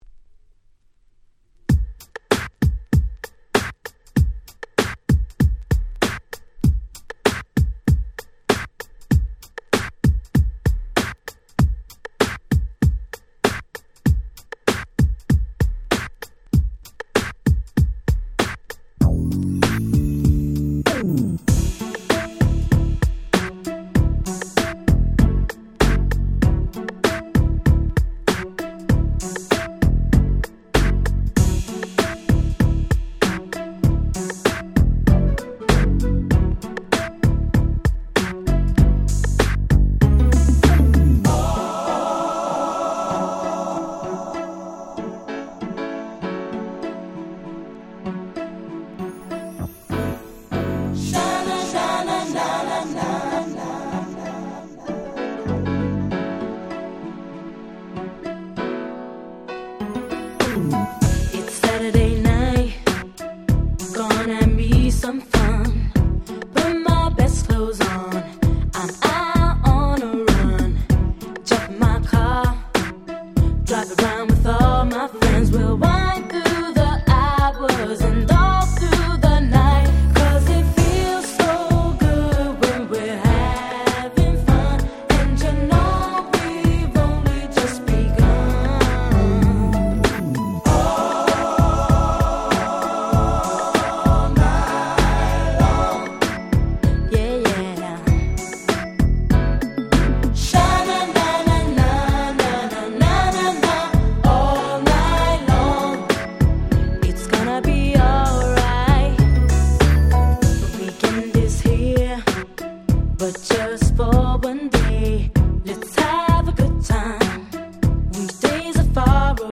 99' Nice EU R&B !!
程良いキャッチーさ加減でまったり進行の凄く良い曲です！